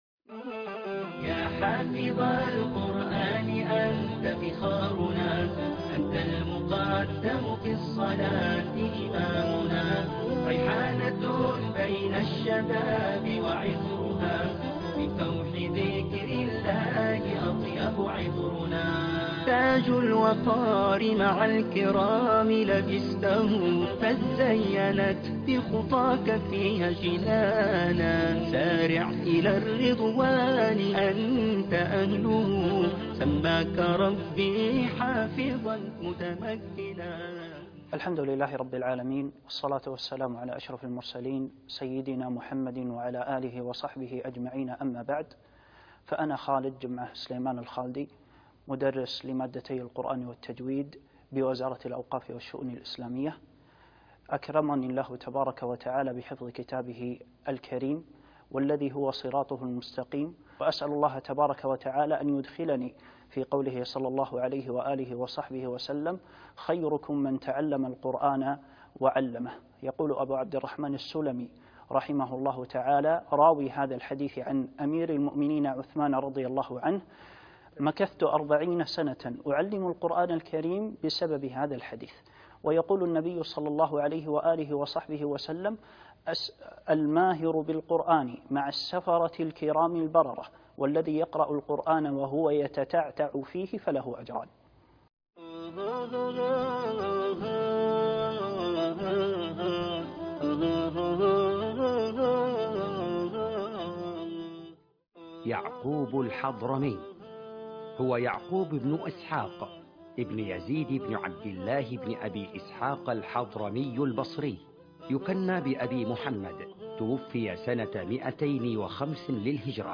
القاريء